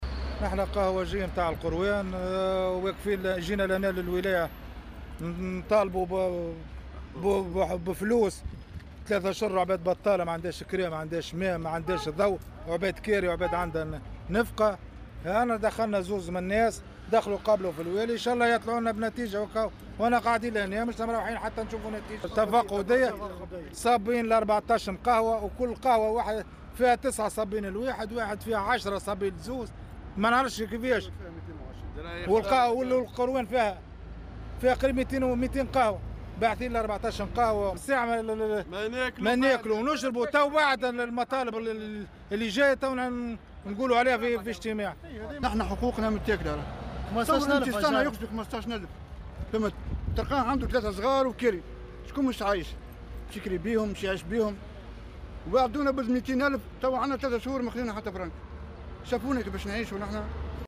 نفذ اليوم الثلاثاء عمّال المقاهي وقفة احتجاجية أمام مقر ولاية القيروان، وذلك للمطالبة بإيجاد حلول لهم بسبب تردي وضعياتهم المالية والاجتماعية الناجمة عن قرار غلق المقاهي في ظل المخاوف من انتشار فيروس "كورونا".